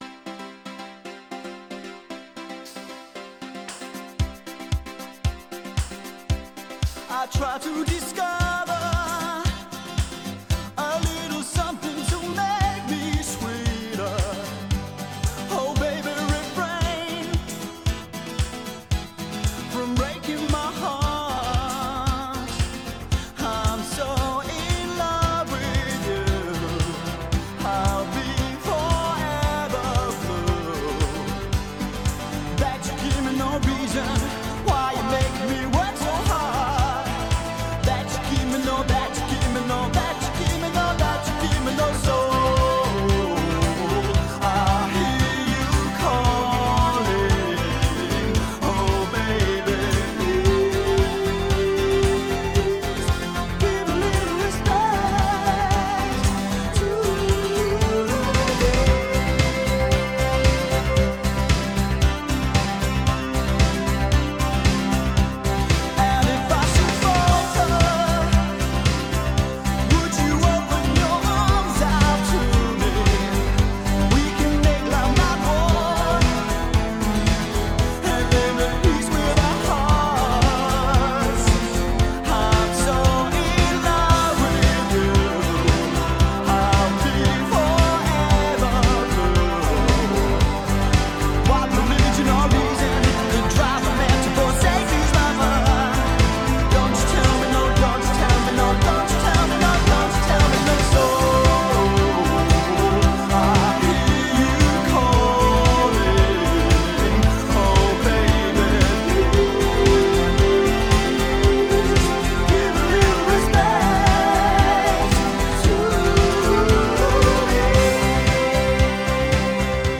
BPM114
Audio QualityMusic Cut